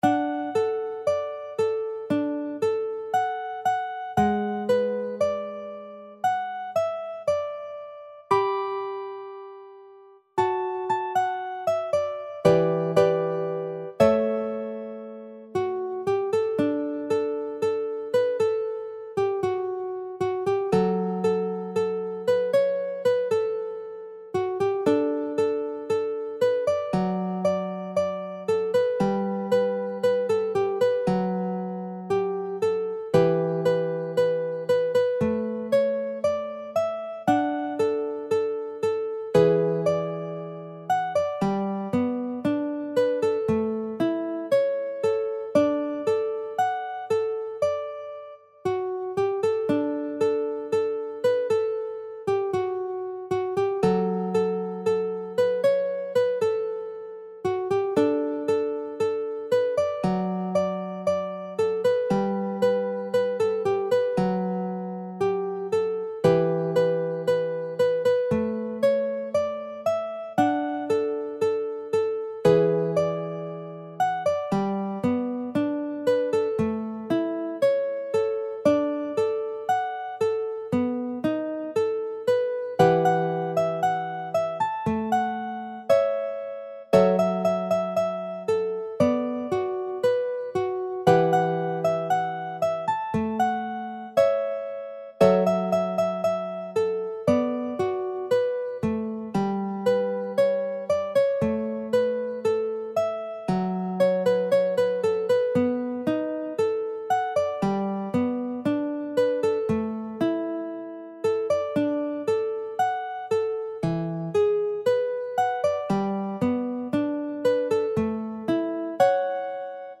J-POP / ポップス
楽譜の音源（デモ演奏）は下記URLよりご確認いただけます。
（この音源はコンピューターによる演奏ですが、実際に人が演奏することで、さらに表現豊かで魅力的なサウンドになります！）